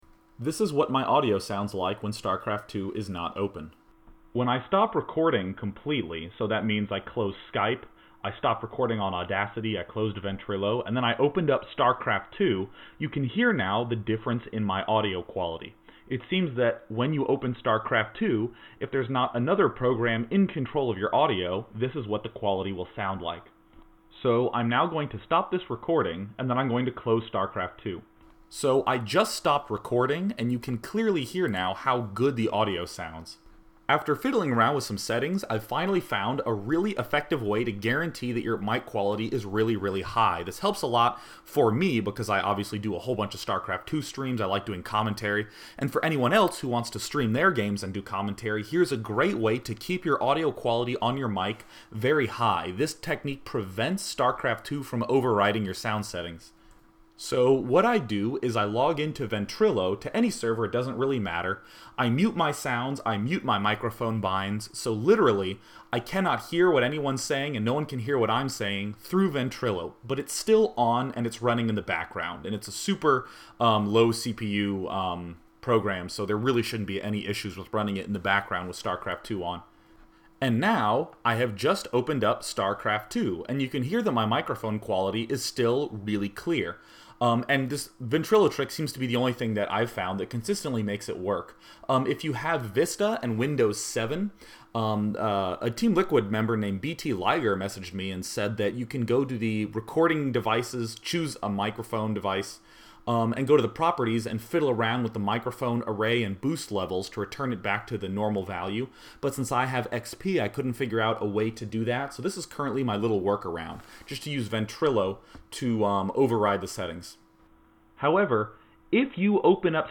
Audio example of SC2 mic problem and how to fix it
For examples of the difference in sound quality, listen to the audio link above.